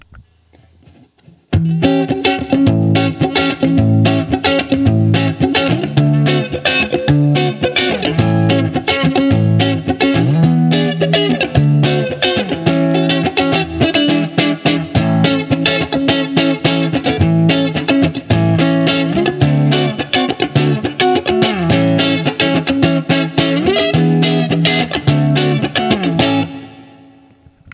With a cleaner amp, it’s still fun to play with a great tone.
All recordings in this section were recorded with an Olympus LS-10.
The Clean setting is “Tiny Tweed”.
Guild-97-Bluesbird-CleanNeck.wav